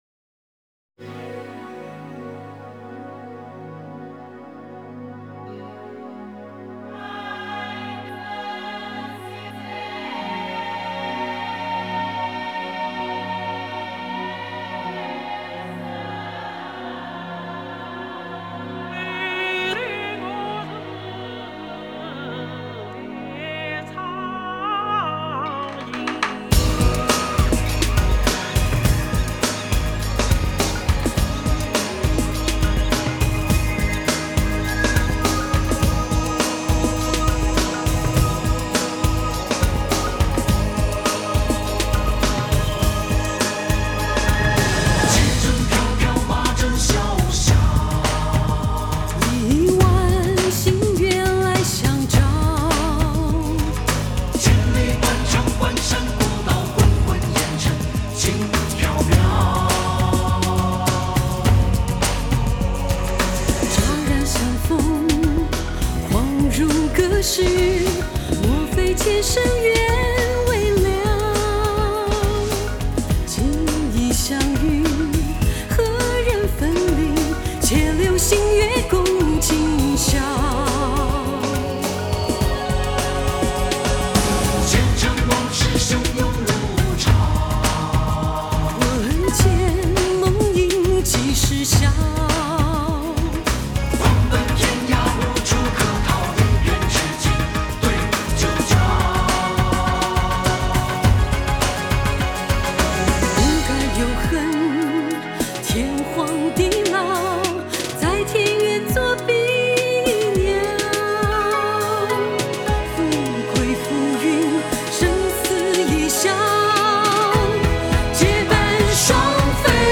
词写得潇洒，歌唱得利落